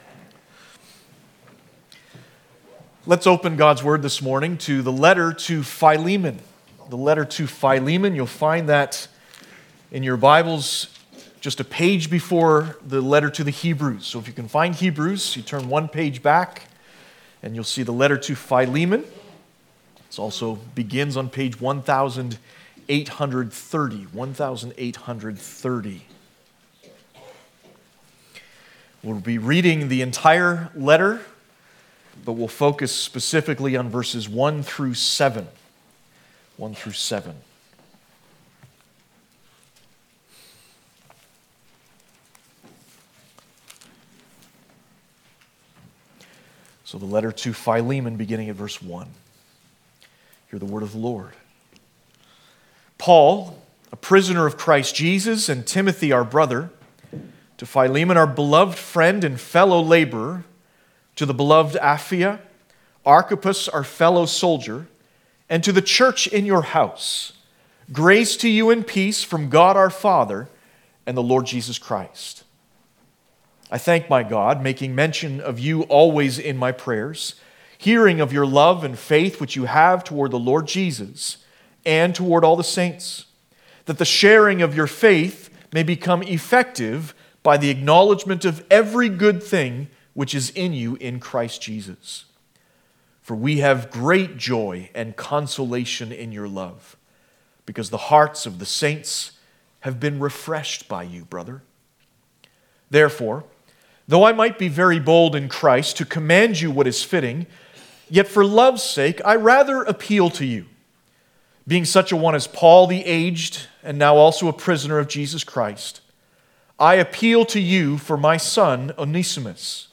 Passage: Philemon 1-7 Service Type: Sunday Morning